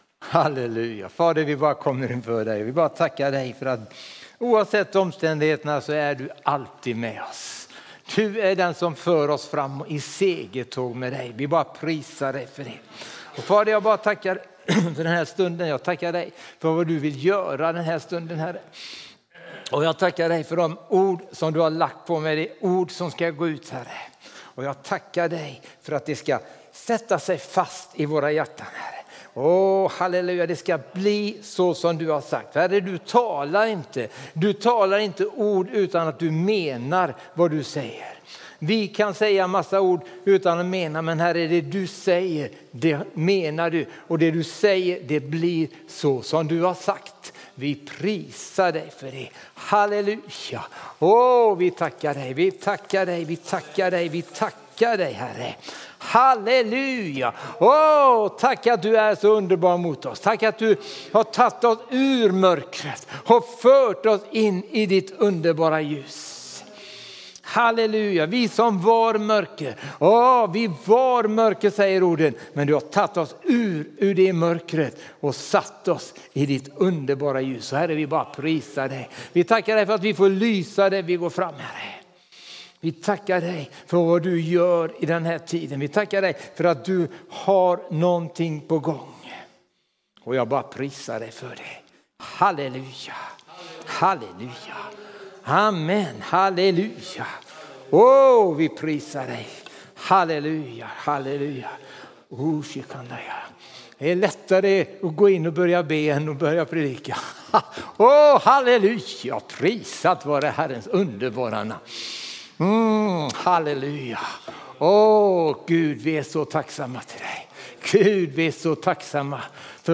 Undervisning och predikningar från Sveriges kyrkor.
Pingstkyrkan Borås tisdag 23 september 2025